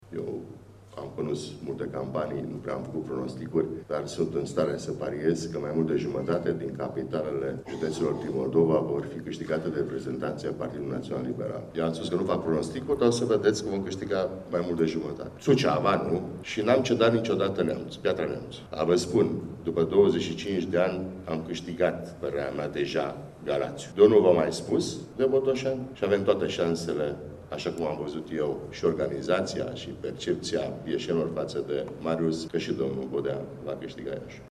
În prezența co-președinților PNL, Alina Gorghiu și Vasile Blaga, astăzi, la Iași, a avut loc lansarea candidaților pentru funcțiile de primari și consilieri locali și județeni.
Referindu-se la Marius Bodea, Vasile Blaga a spus că acesta are şanse reale în competiţia electorală pentru Primăria Municipiului Iaşi: